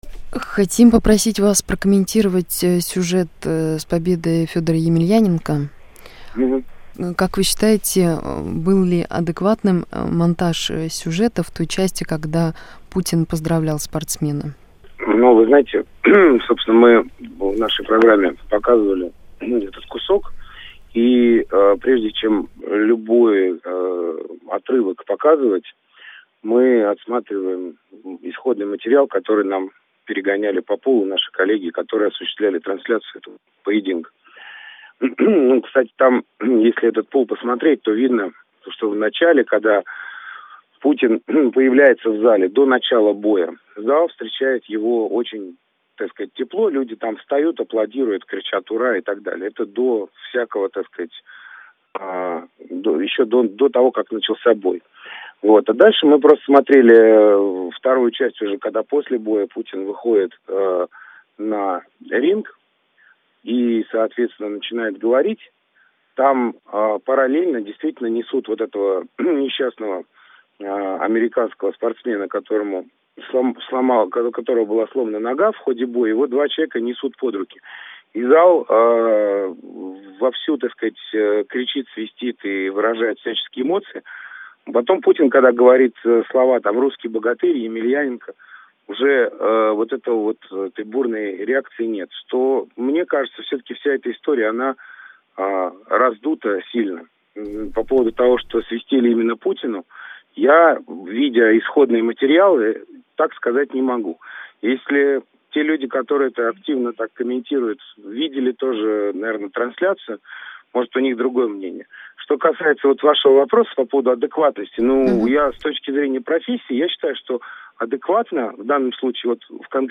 Почему сюжет "Времени", рассказывающий о выступлении Путина в "Олимпийском", отличается от любительской съемки? Интервью с телеведущим Петром Толстым.